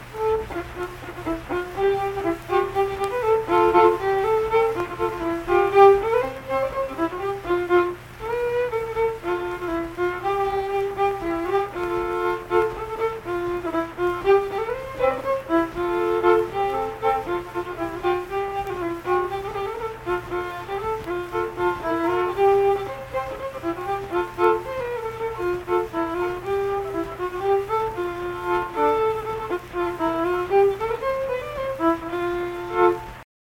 Unaccompanied vocal and fiddle music
Verse-refrain 2(2).
Instrumental Music
Fiddle
Saint Marys (W. Va.), Pleasants County (W. Va.)